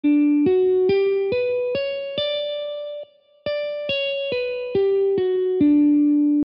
Next: A rundown of 22 notable murchana sets, unearthed via my Ragatable analysis (plus audio demos on my santoor)…